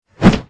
zombie_swing_3.wav